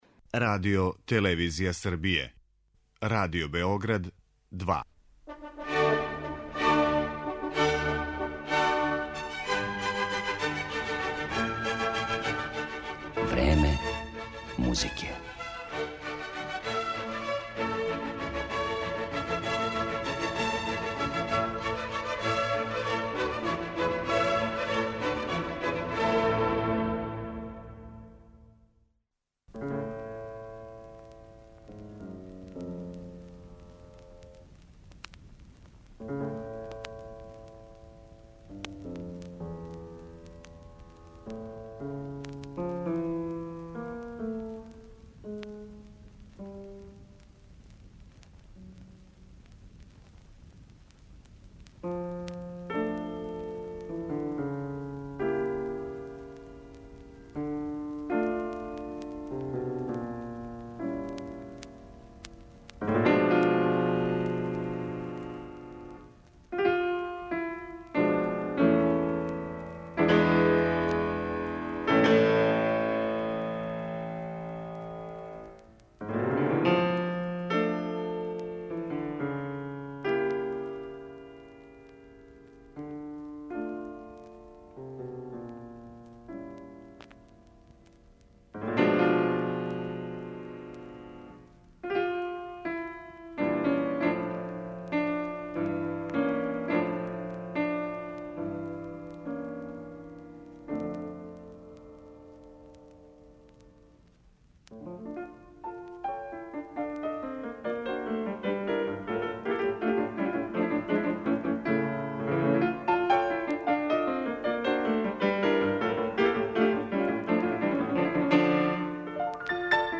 Емисија је посвећена клавирском дуу.